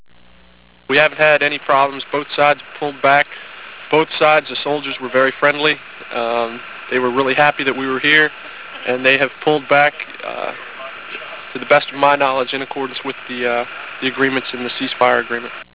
In Tuzla, Bosnia